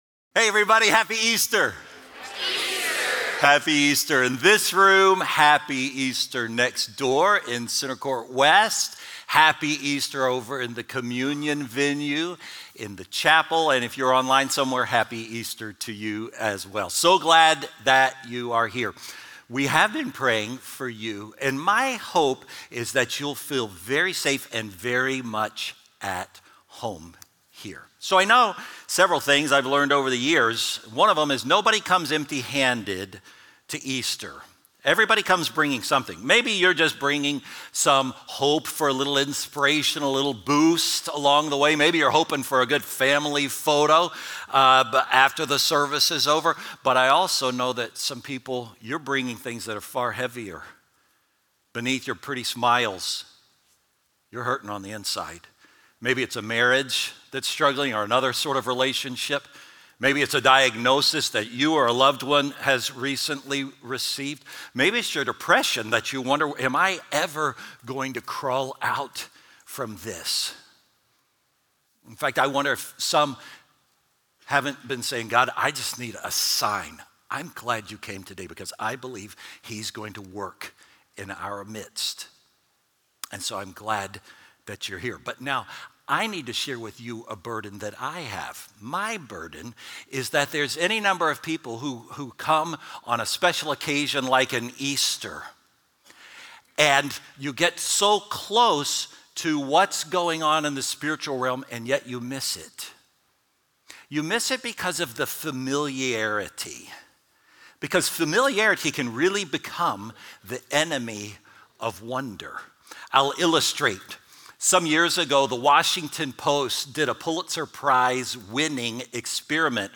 Faithbridge Sermons